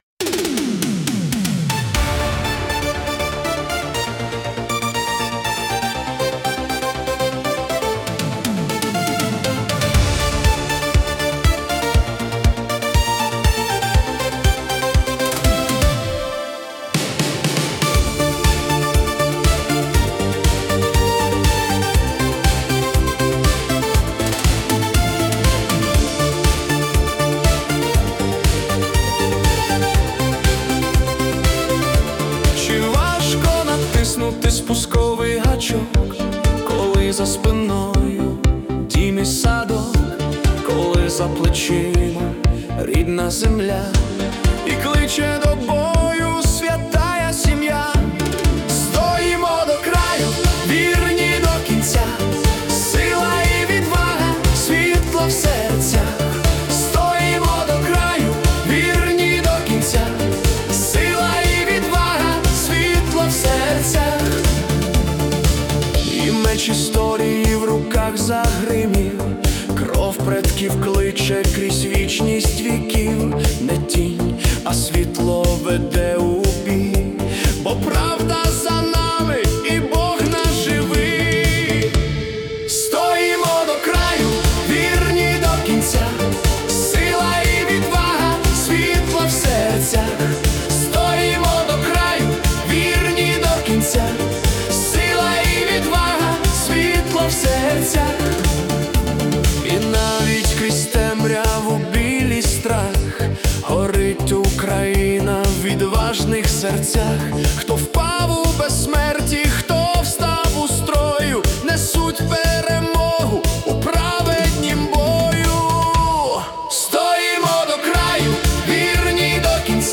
🎵 Жанр: Italo Disco / Epic Anthem Fusion
Приспів пісні звучить як бойовий клич на танцполі.